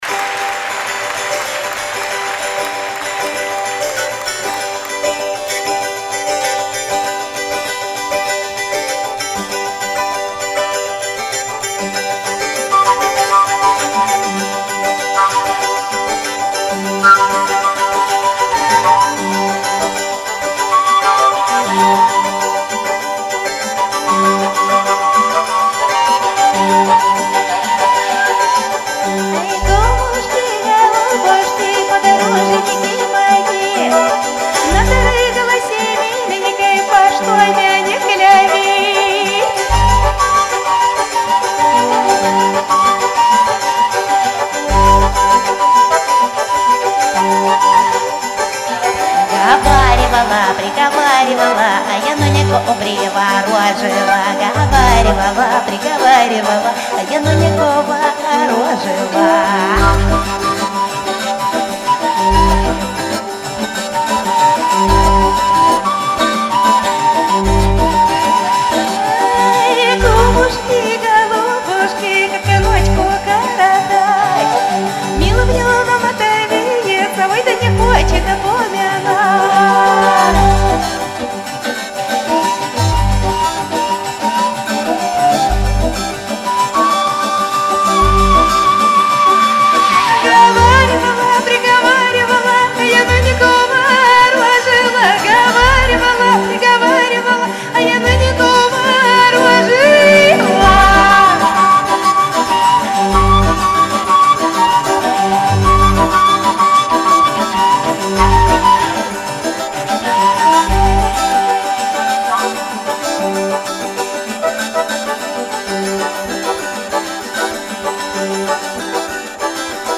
Славянская музыка